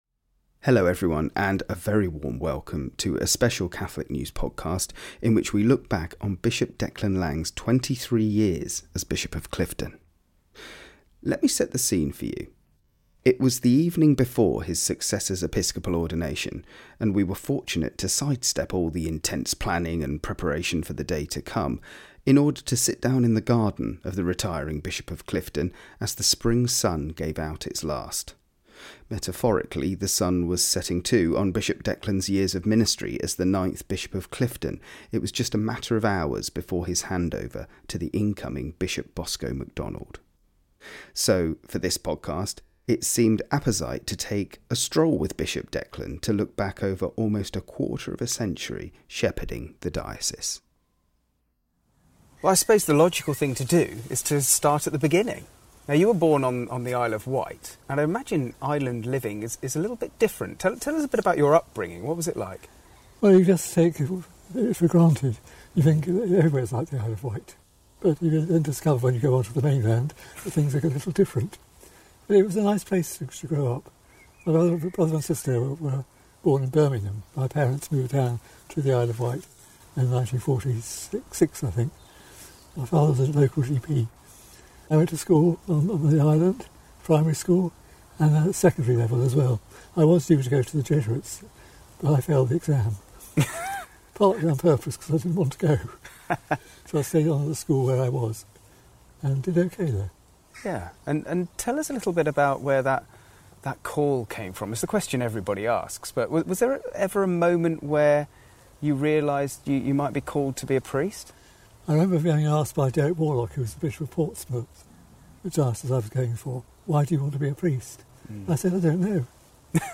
It was the evening before his successor's episcopal ordination, and we were fortunate to side-step all the intense planning and preparation for the day to come, in order to sit down in the garden of the retiring Bishop of Clifton as the Spring sun gave out its last.